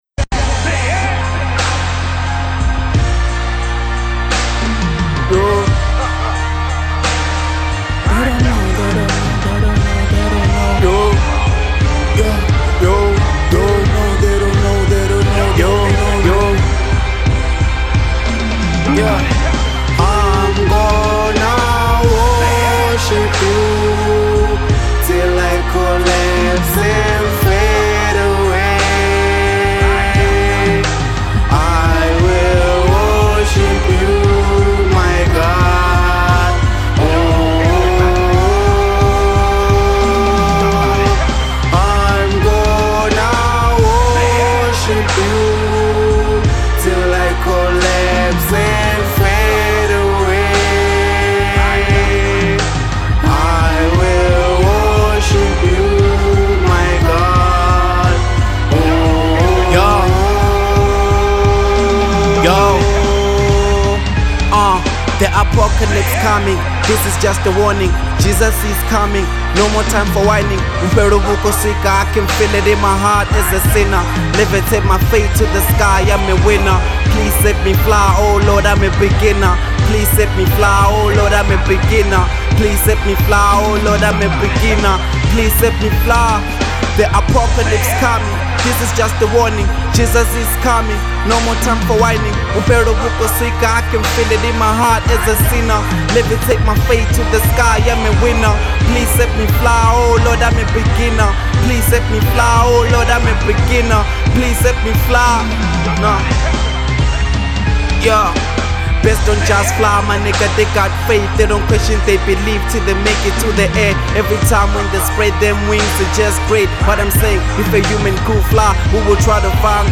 03:33 Genre : Venrap Size